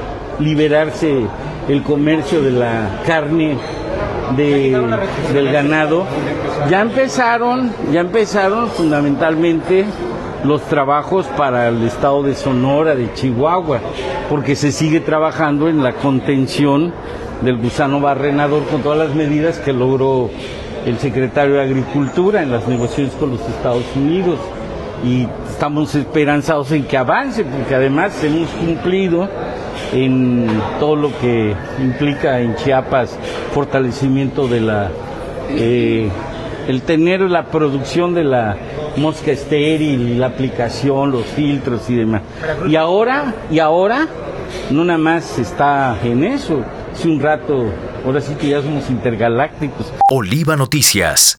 En entrevista, remarcó la decisión del Departamento de Agricultura de Estados Unidos (USDA), para el ingreso del ganado vacuno, bisontes y equinos.